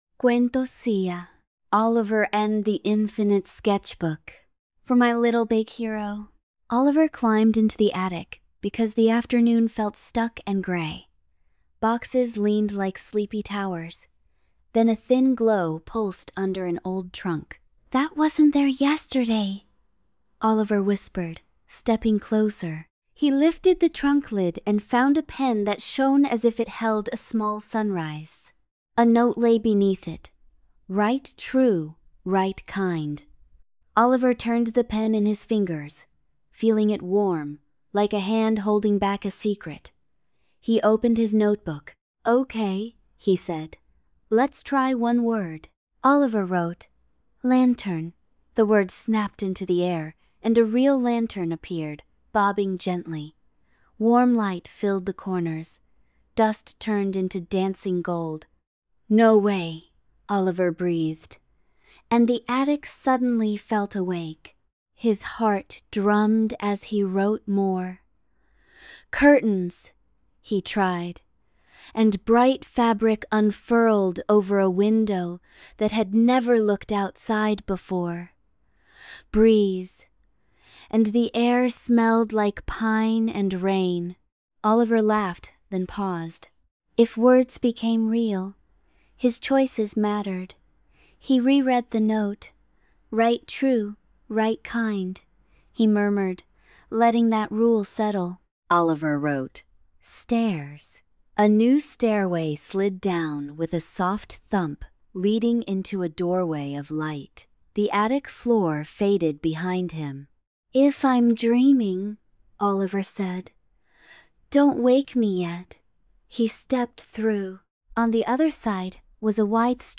Narrated Audiobook